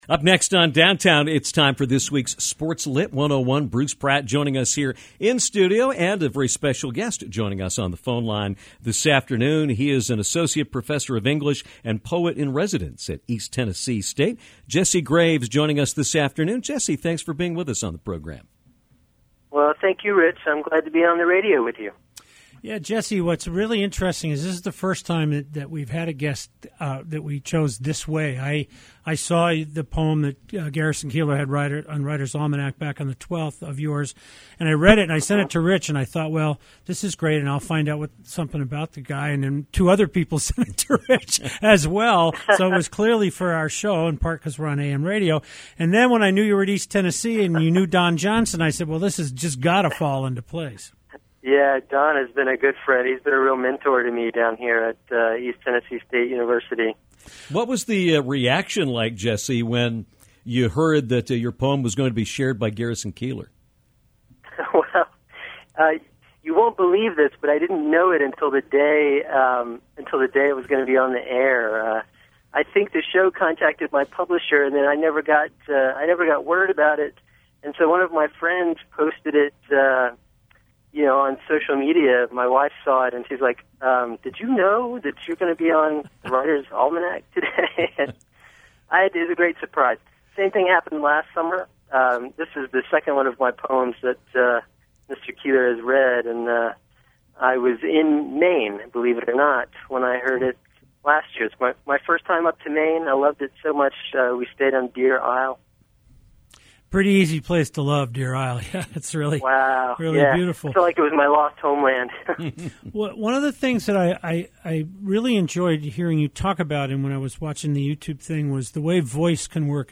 to talk about his work and recited his poem